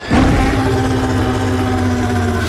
File:Skar King roar.mp3